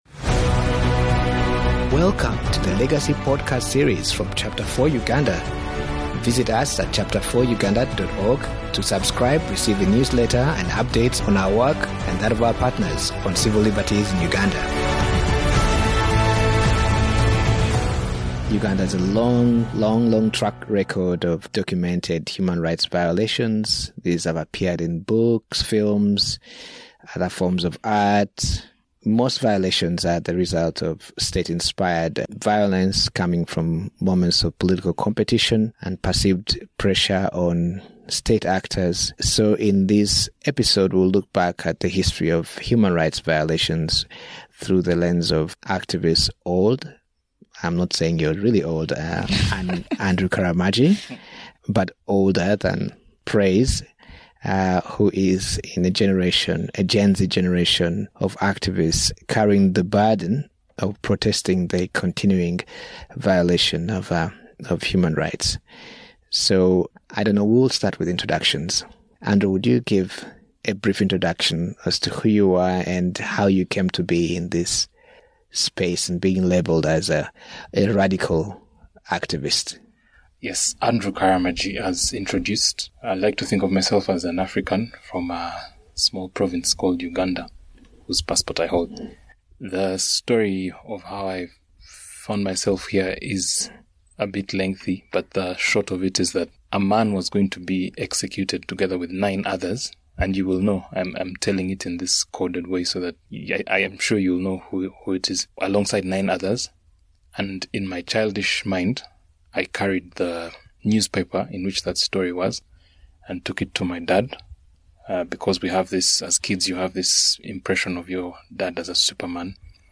However, both activists agree on the necessity of coupling digital activism with physical peaceful protests to counter the state's impunity.